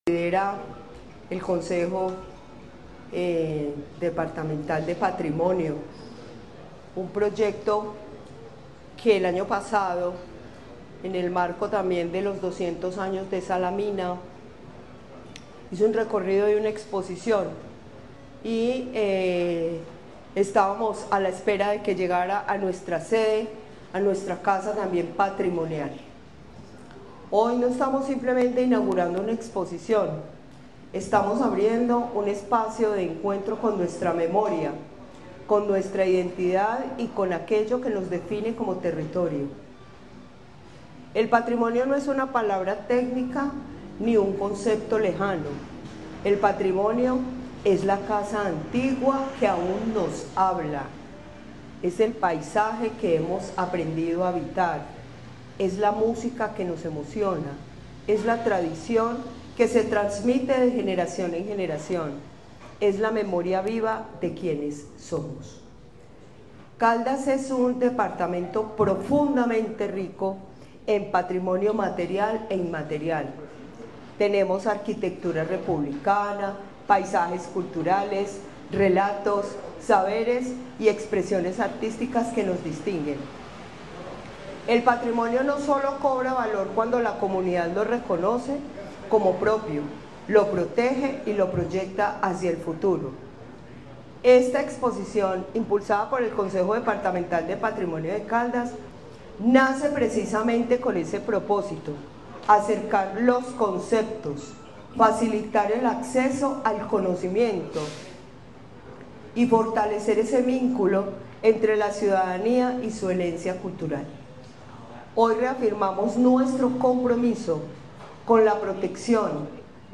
Durante la inauguración, la secretaria de Cultura de Caldas, Luz Elena Castaño Rendón, destacó que este espacio permite reencontrarse con la identidad del territorio y entender el patrimonio como una construcción viva que forma parte de la historia compartida.
Luz Elena Castaño Rendón, secretaria de Cultura de Caldas